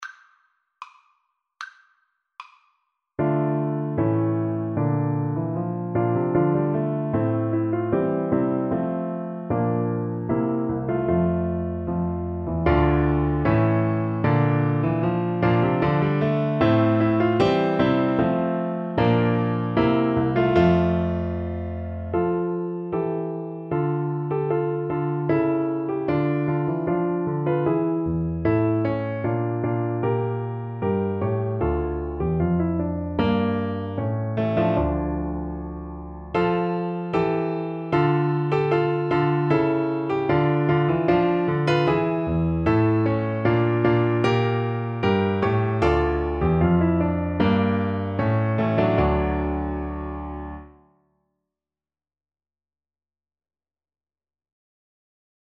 Steadily =c.76
Classical (View more Classical French Horn Music)